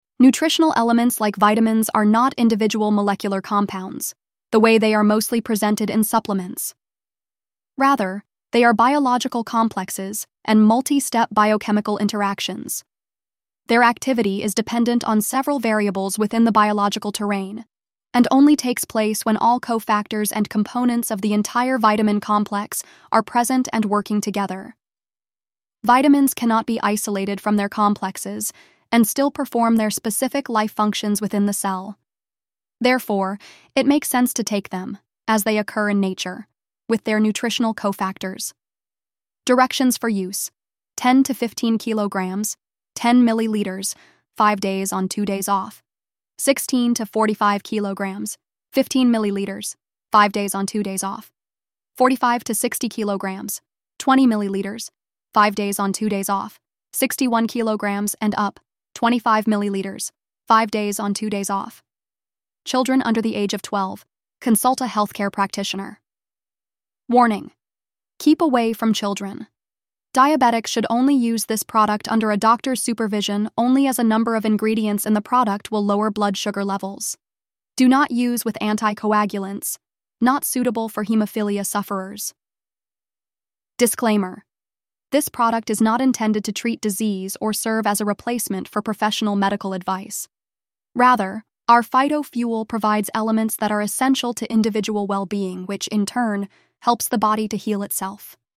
Narrated Product Information.
Oshun-Health-Phyto-Fuel-Voiceover.mp3